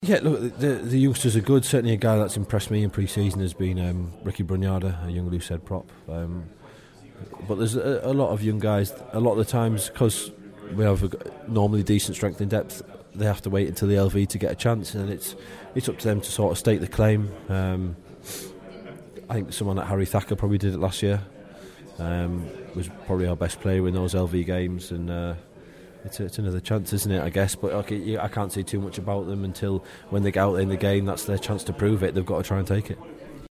at the Premiership launch about the strength in depth at the club.